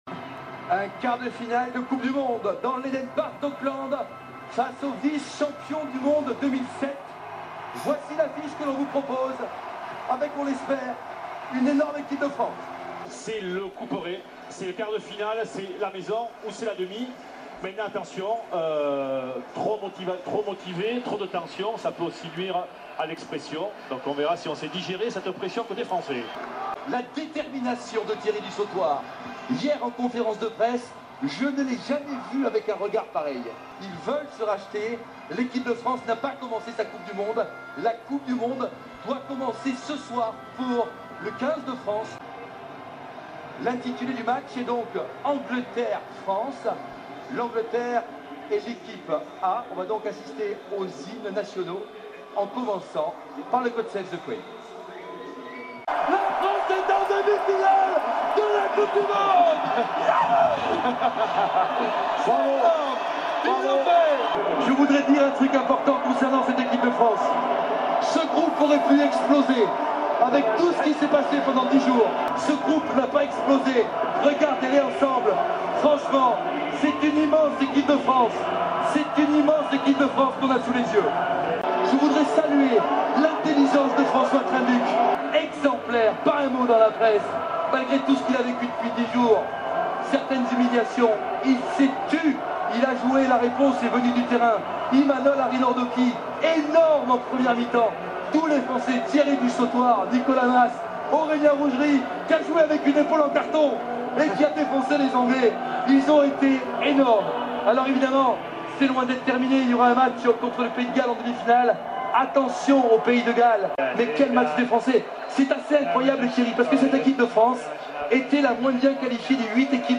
Puis les commentaires des journalistes avant et après le match, pour se rappeler comme les relations entre une équipe, son entraîneur, ses supporters, son public et les journalistes peuvent se situer dans le domaine des émotions !
Le tout dans un français qui va vite !